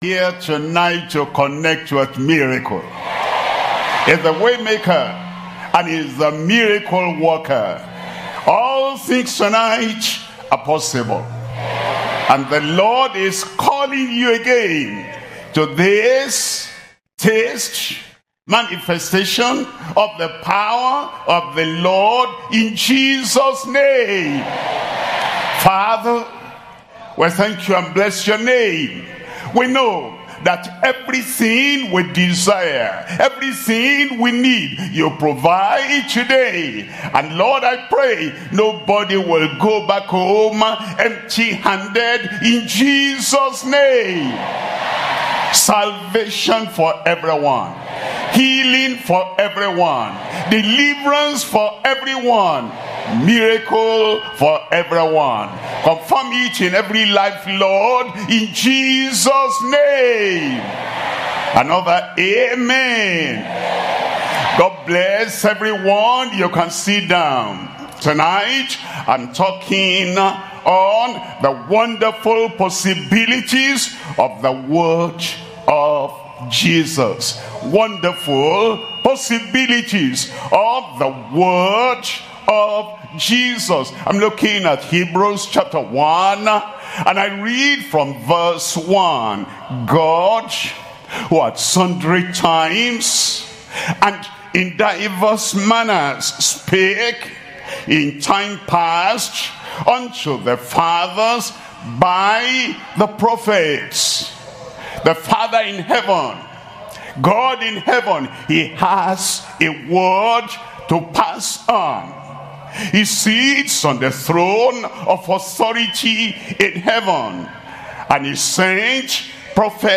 SERMONS – Deeper Christian Life Ministry Australia